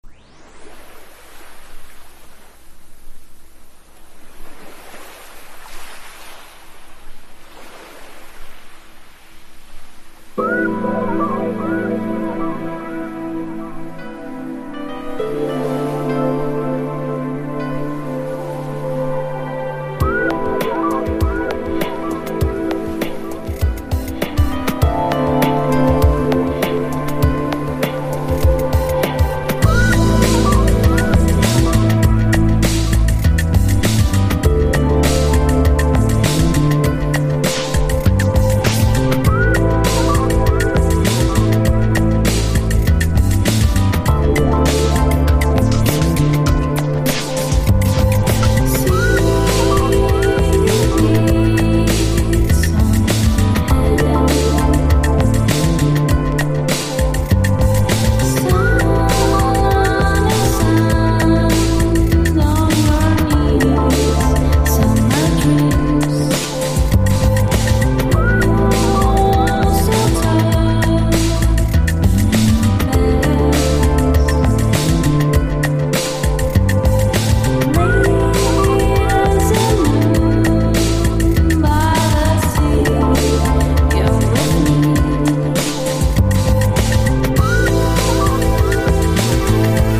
耽美なダウンテンポ～エレクトロニカ～チルウェイヴを軸に様々な要素がクロスオーバーする個性あふれる6トラック。